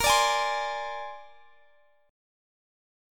Bb9 Chord
Listen to Bb9 strummed